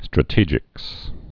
(strə-tējĭks)